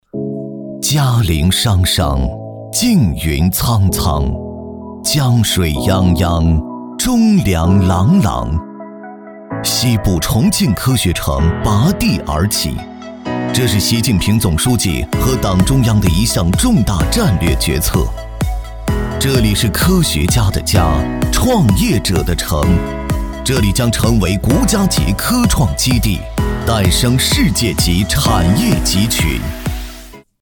宣传片-男15-重庆科学城.mp3